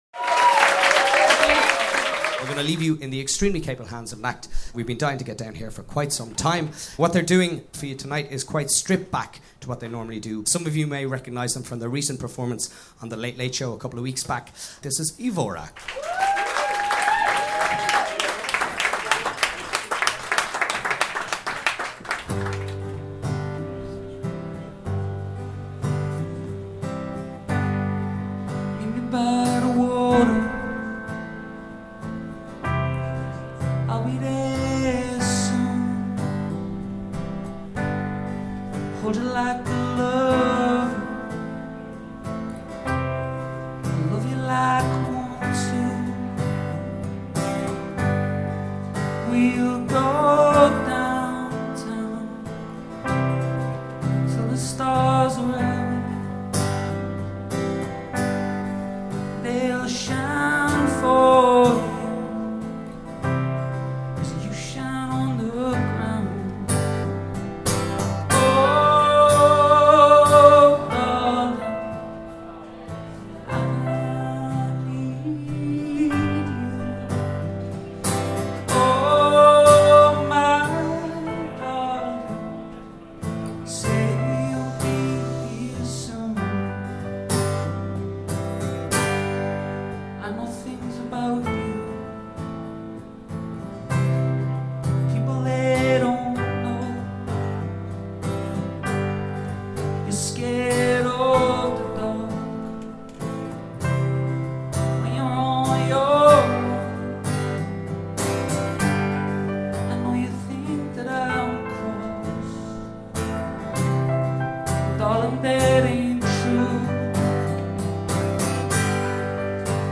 Dublin band
perform a few acoustic tracks